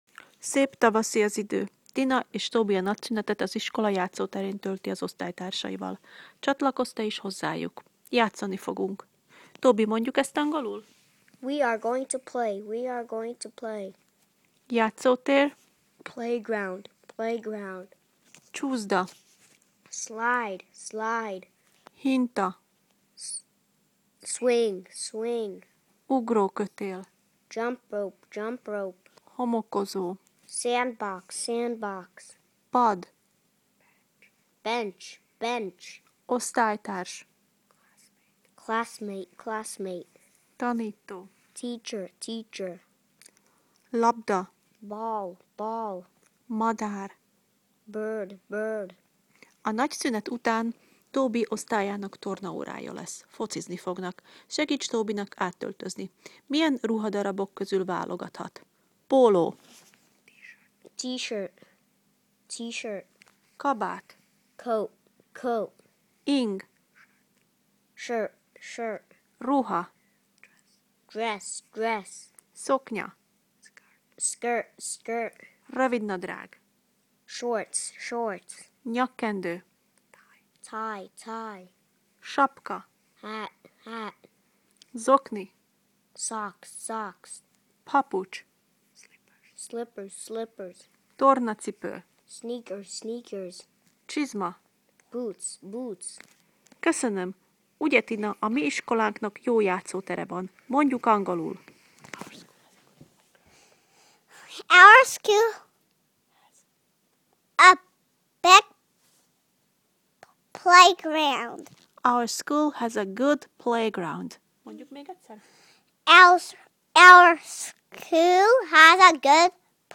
Az ebben a leckében szereplő szavak helyes kiejtését meghallgathatod Tobytól.